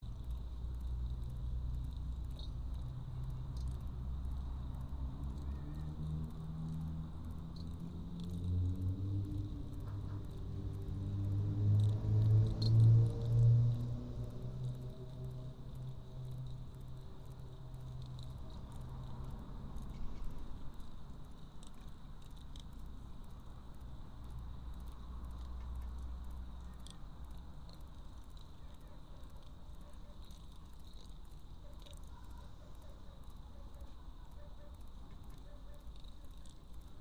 Live from Soundcamp: La Escocesa mixing in Barcelona (Audio) Sep 13, 2025 shows Live from Soundcamp A listening/mixing station will combine live streams of back yards in Barcelona and beyond Play In New Tab (audio/mpeg) Download (audio/mpeg)